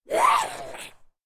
femalezombie_chase_04.ogg